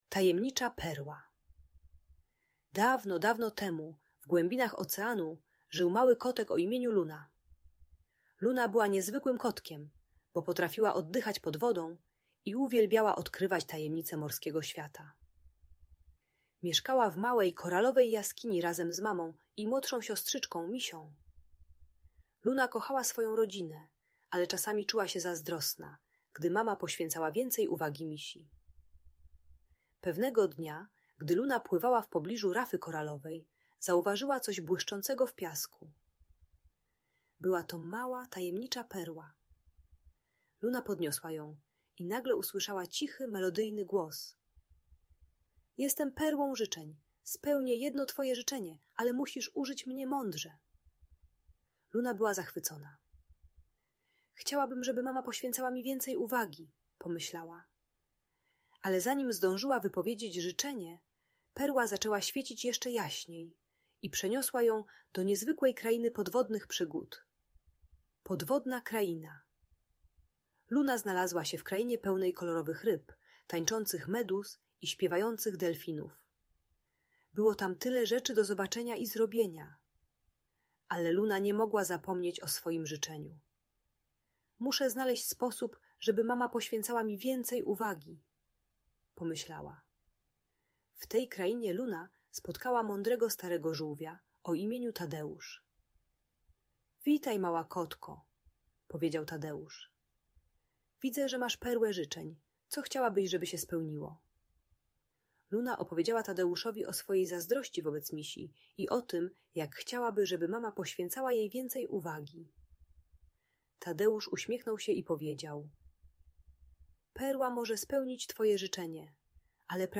Tajemnicza Perła: Podwodna Przygoda Luny - Audiobajka